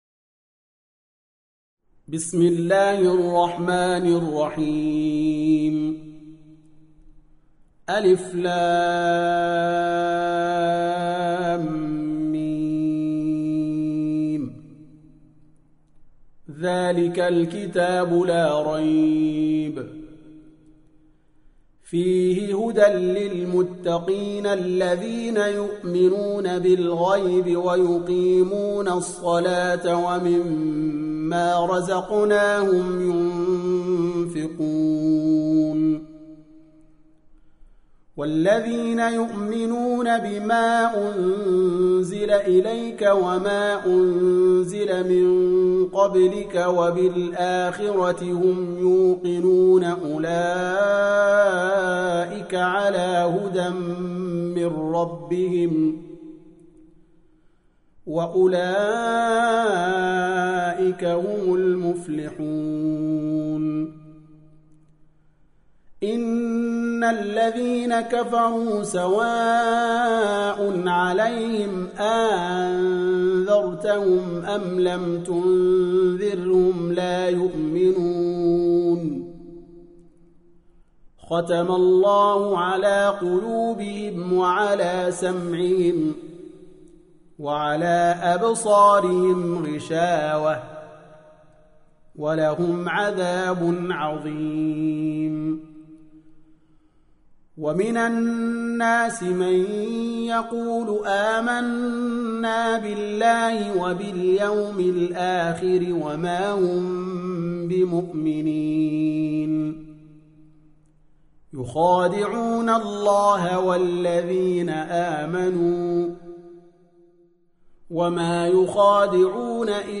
2. Surah Al-Baqarah سورة البقرة Audio Quran Tarteel Recitation
Surah Repeating تكرار السورة Download Surah حمّل السورة Reciting Murattalah Audio for 2.